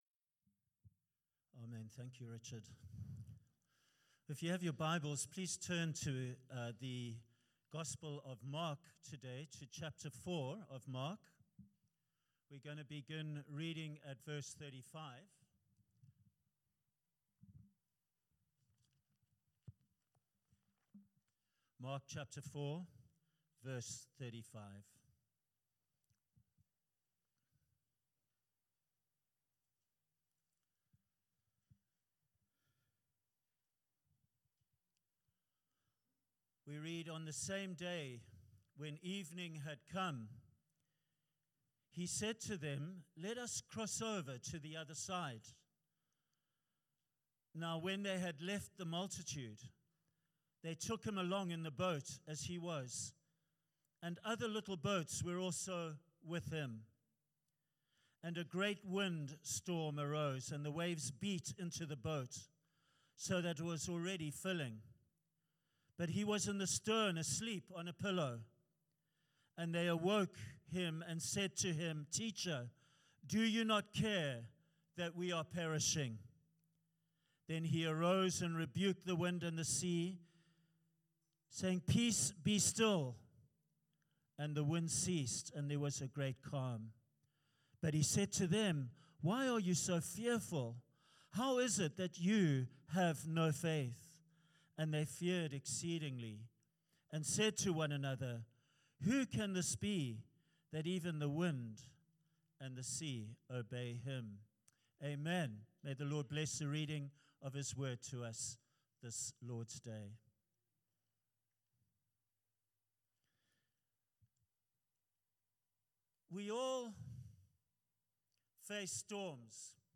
A Sunday sermon